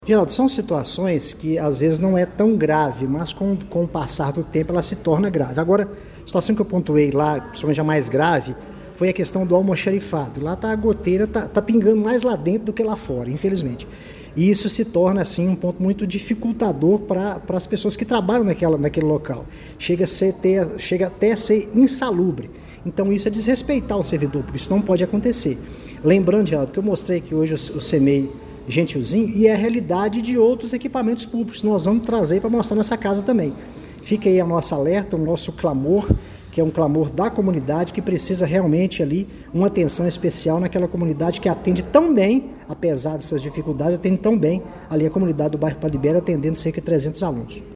A situação do Centro Municipal de Educação Infantil Prefeito José Gentil de Almeida, conhecido como Gentilzinho, no bairro Padre Libério, foi levada à tribuna da Câmara Municipal de Pará de Minas durante a reunião ordinária desta terça-feira (19).